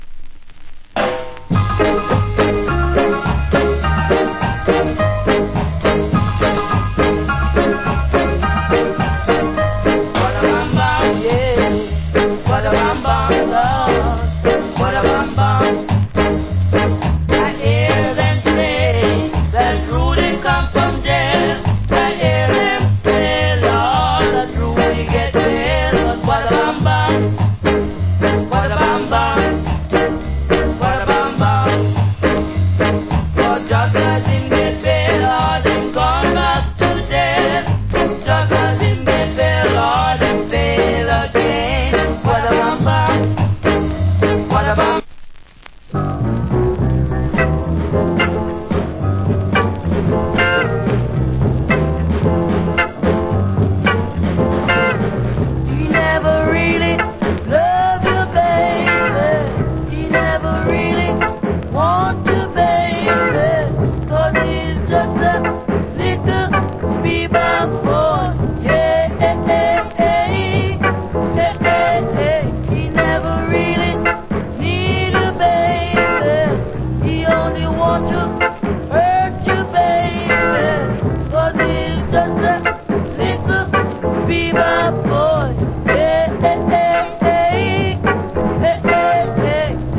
Nice Rock Steady Vocal.